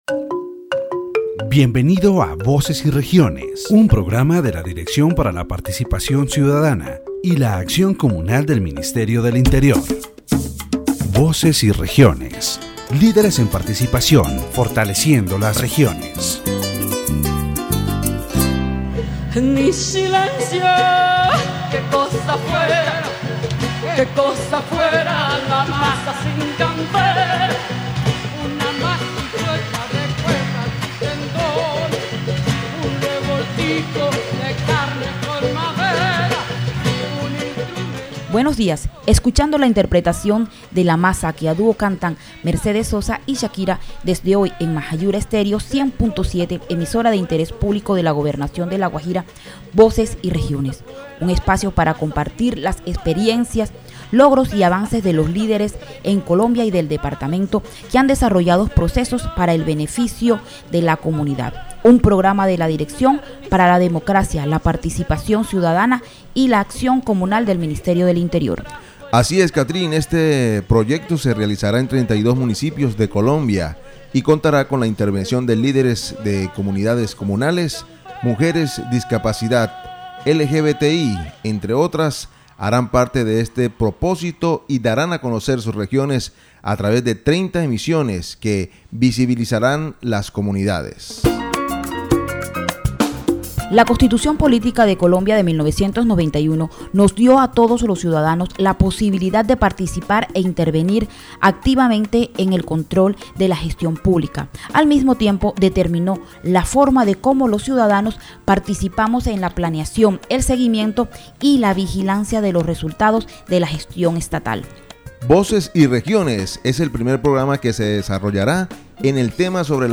The radio program "Voces y Regiones," broadcast on Majayura Stereo 100.7 FM, highlights the importance of citizen participation in public management. This edition discussed participatory processes as mechanisms for policy formulation and social inclusion in La Guajira. It analyzed how communities can influence government decision-making, the impact of participatory budgets, and strategies to strengthen community development.